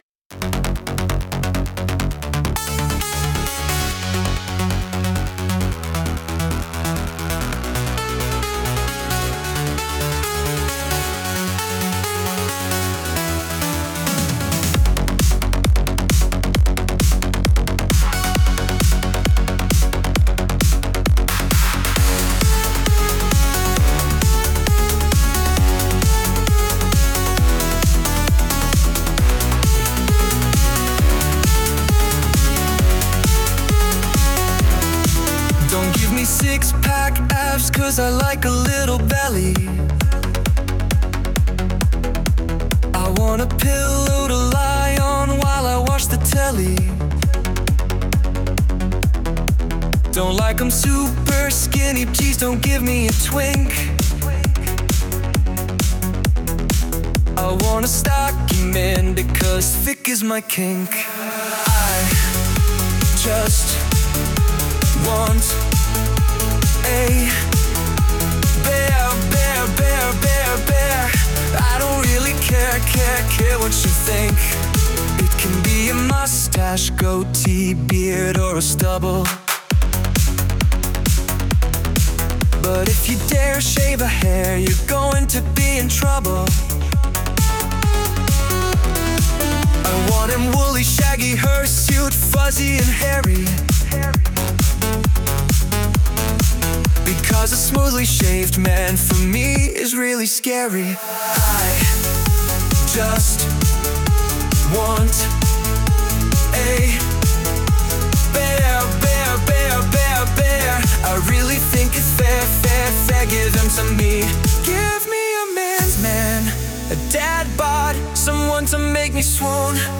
So here’s the club mix version.